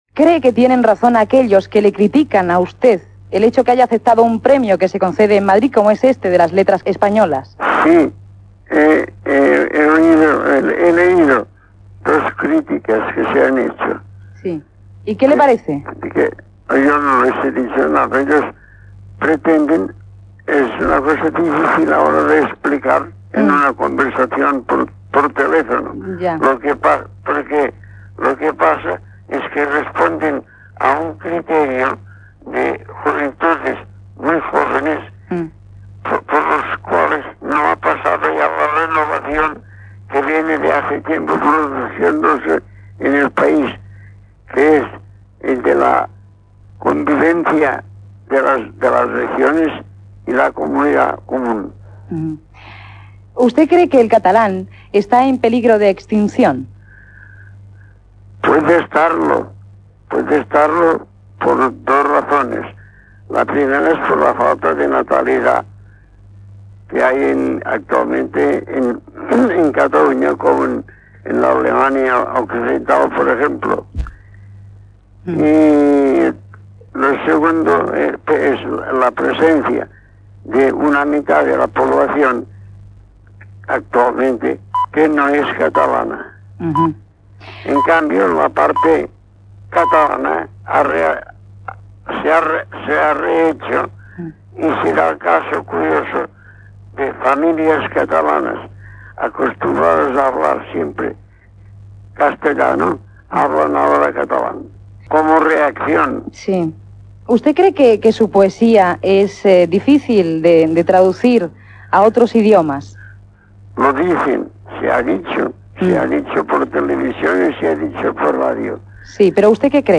Entrevista Josep Vicent Foix - SER, 1984
GEMIO-Isabel-SER-1984-Entrev-Josep-Vicent-Foix.mp3